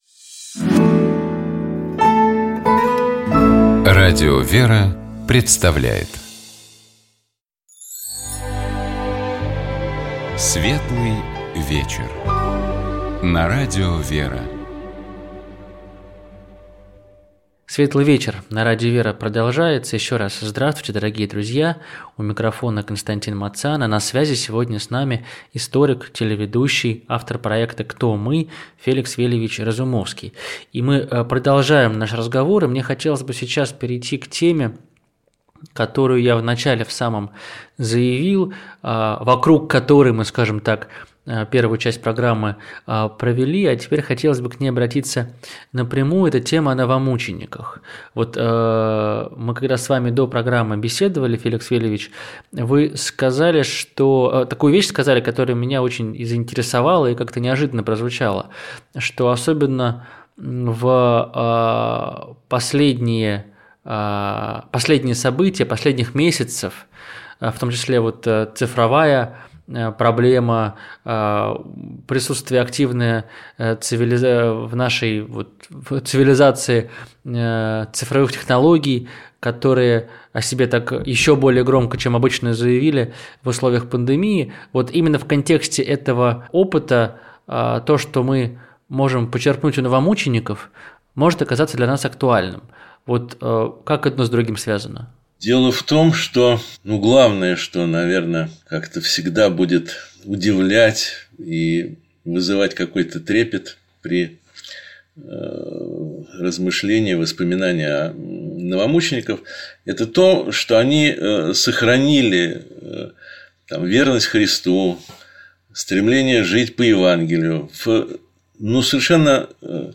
Наш собеседник — историк, писатель и телеведущий Феликс Разумовский.